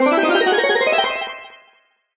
level_up_01.ogg